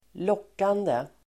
Uttal: [²l'åk:ande]
lockande.mp3